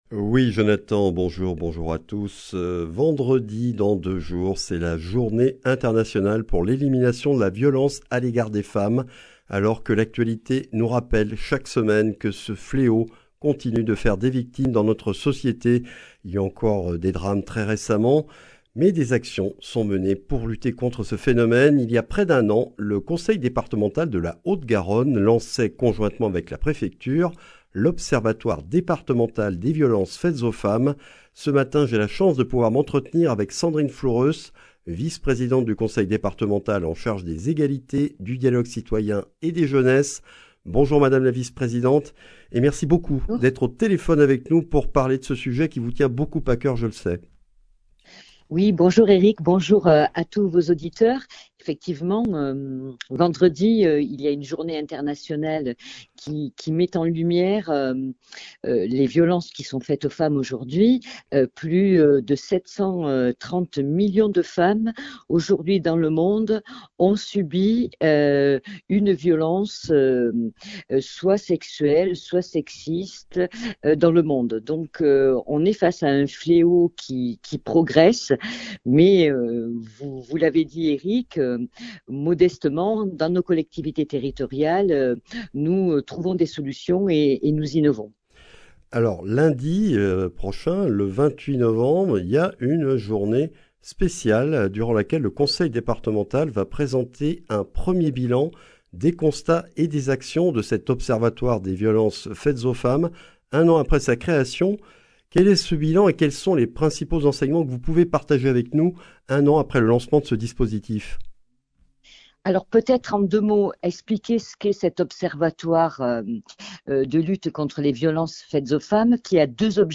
Accueil \ Emissions \ Information \ Régionale \ Le grand entretien \ L’Observatoire départemental des violences faites aux femmes, un an après sa (…)